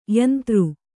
♪ yantř